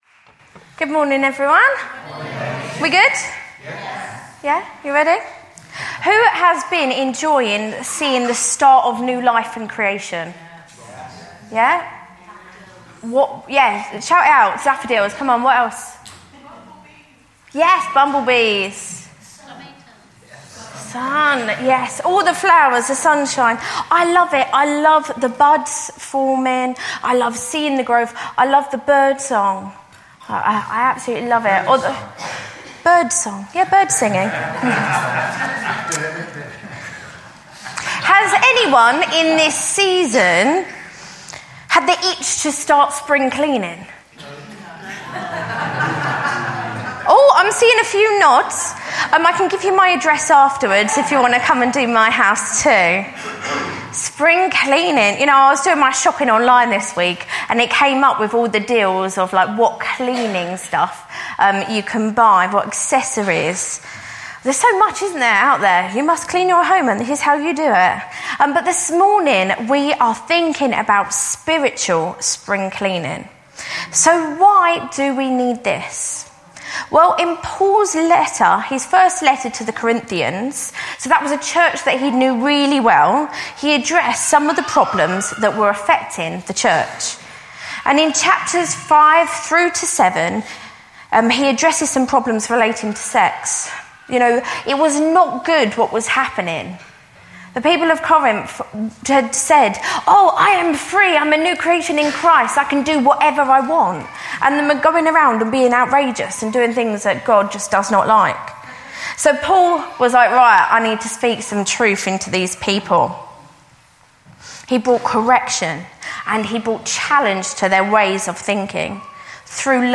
Let Go, Let God Preacher: